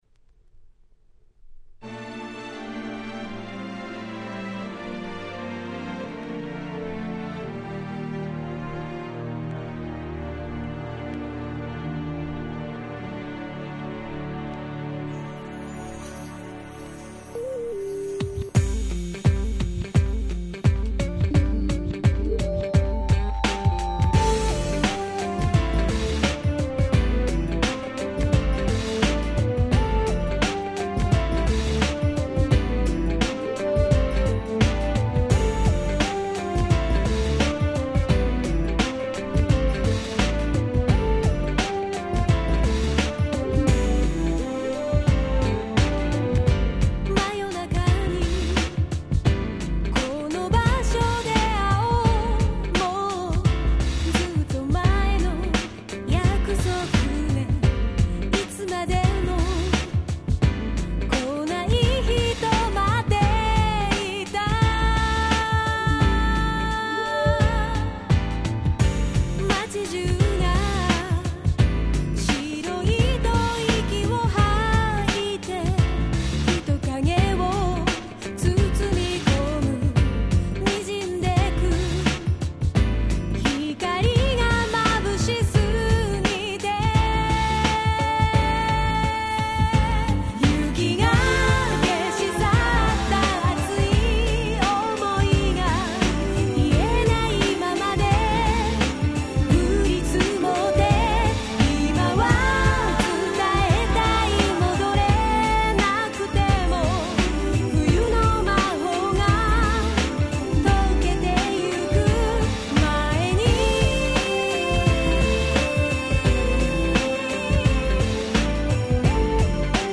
98' Nice Japanese R&B !!